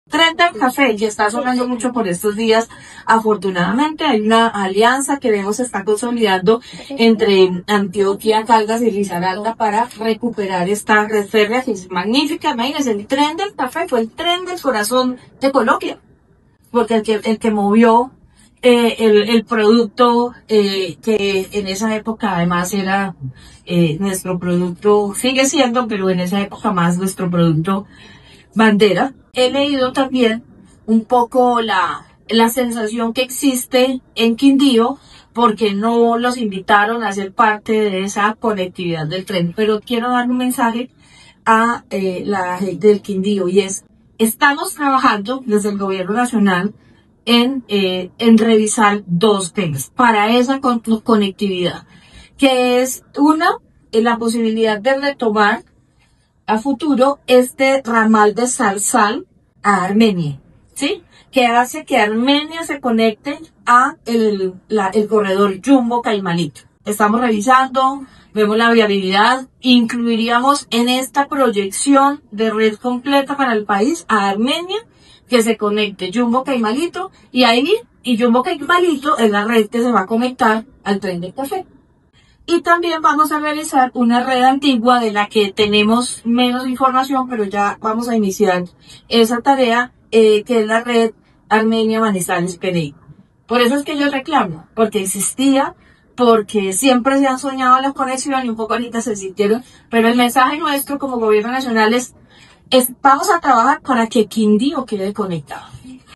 La ministra de Transporte, María Fernanda Rojas, durante su participación en el Foro Internacional por la Reactivación Ferroviaria que se adelanta en Bogotá, destacó los avances de la alianza entre los departamentos de Antioquia, Caldas y Risaralda para consolidar el proyecto del Tren del Café, iniciativa que busca reactivar una infraestructura histórica clave para el desarrollo del país.
María Fernanda Rojas, ministra de Transporte.
Audio-Maria-Fernanda-Rojas-ministra-de-Transporte.mp3